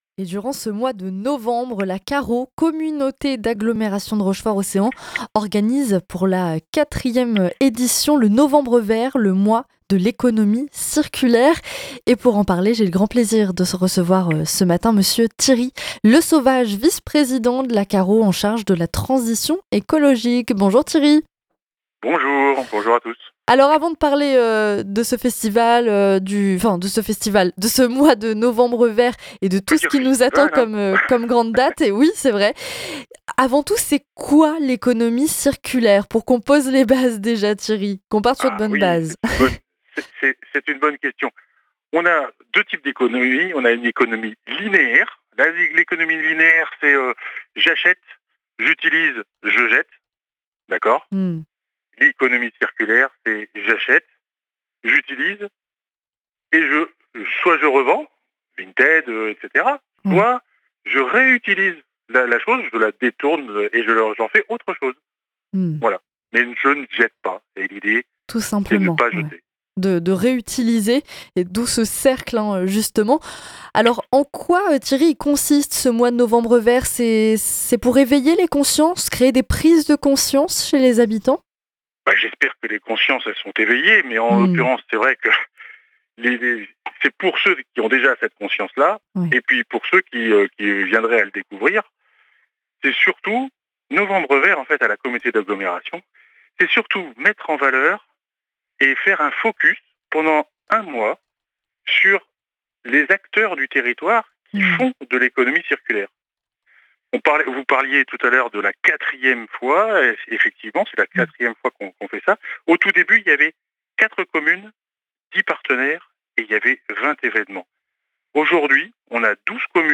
M. Thierry LESAUVAGE, vice-président de la CARO en charge de la transition écologique, nous en a parlé dans une interview.